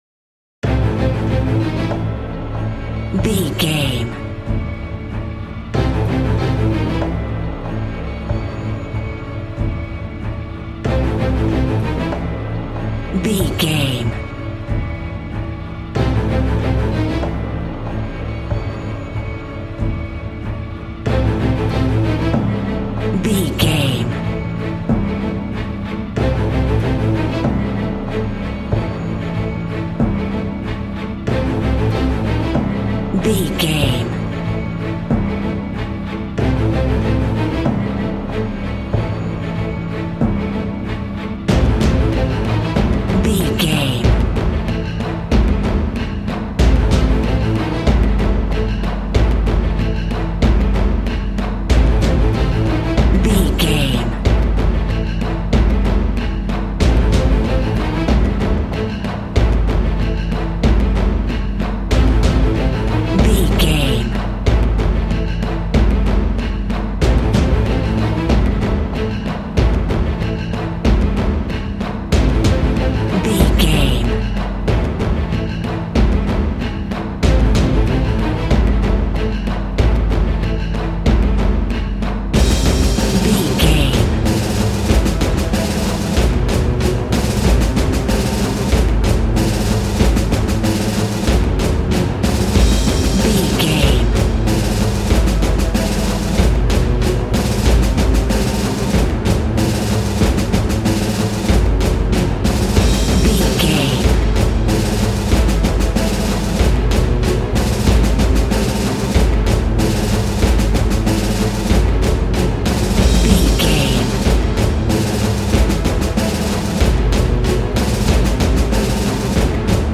Epic / Action
Fast paced
In-crescendo
Uplifting
Aeolian/Minor
dramatic
powerful
strings
brass
percussion
synthesiser